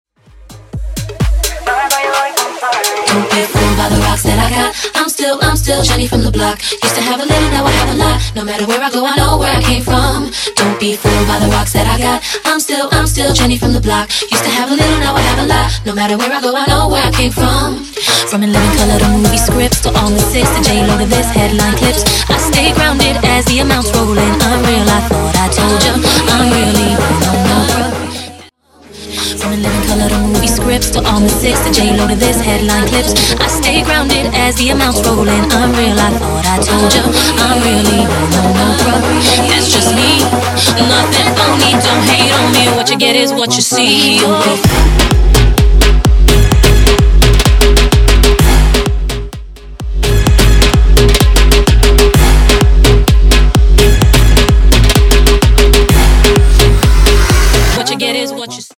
BOOTLEG , TOP40